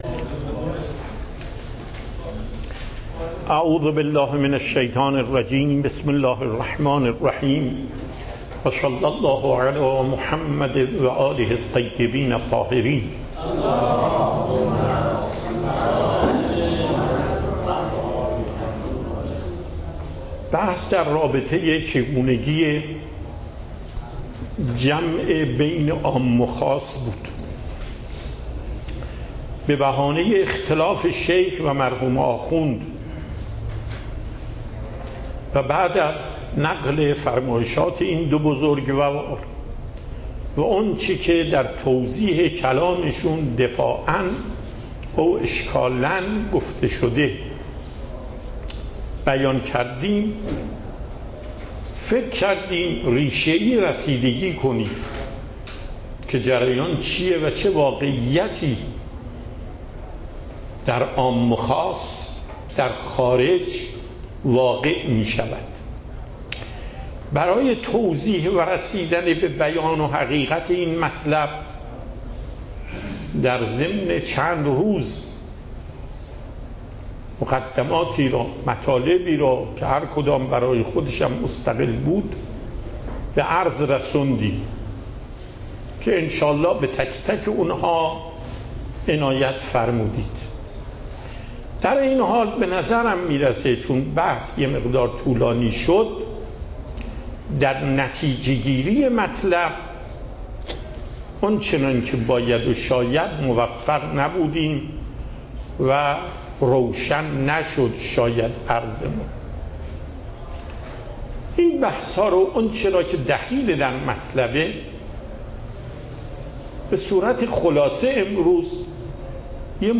صوت و تقریر درس پخش صوت درس: متن تقریر درس: ↓↓↓ تقریری ثبت نشده است.
درس اصول آیت الله محقق داماد